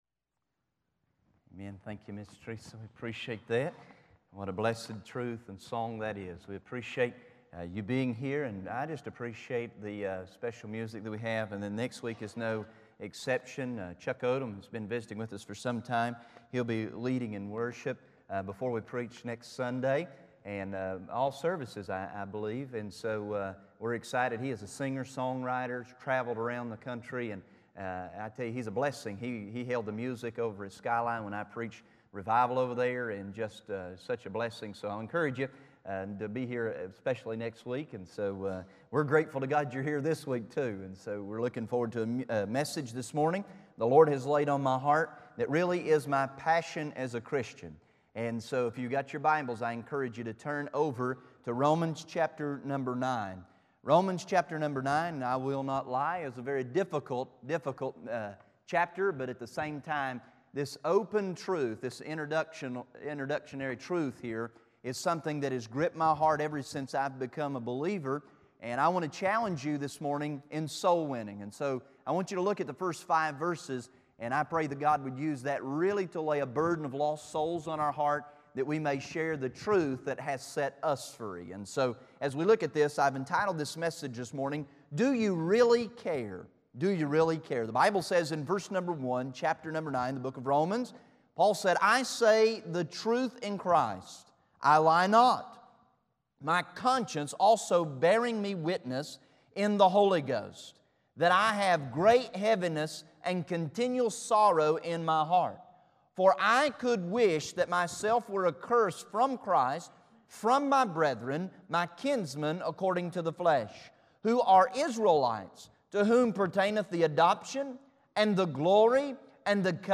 August 2010 Sermon Library